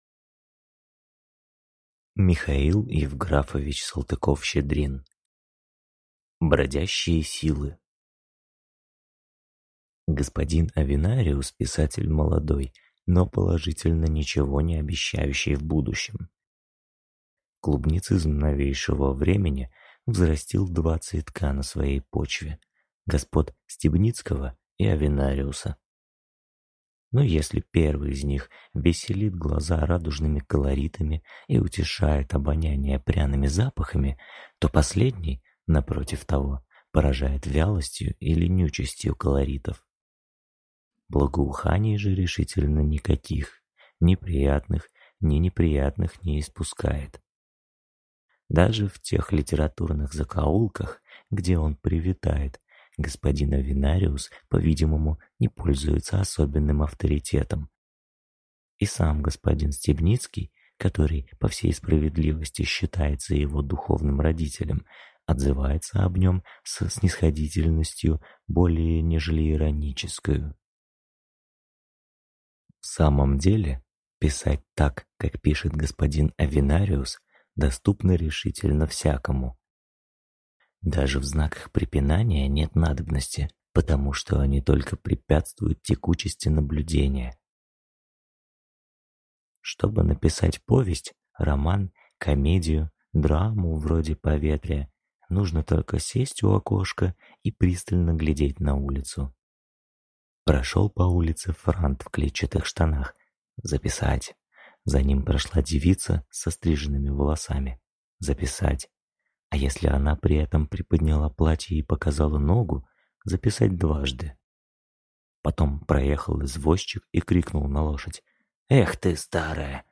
Аудиокнига Бродящие силы